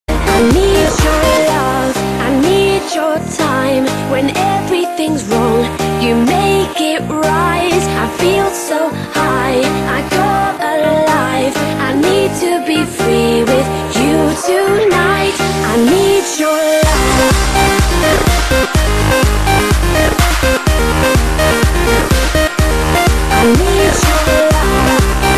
M4R铃声, MP3铃声, 欧美歌曲 91 首发日期：2018-05-15 14:56 星期二